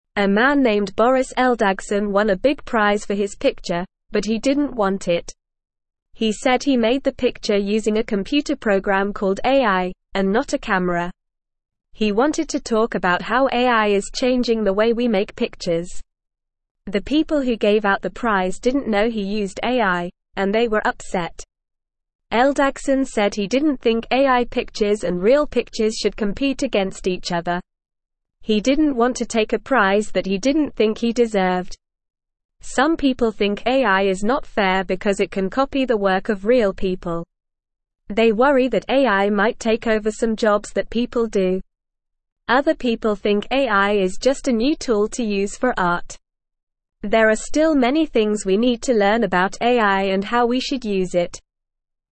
Normal
English-Newsroom-Beginner-NORMAL-Reading-Man-Wins-Picture-Prize-Gives-It-Back.mp3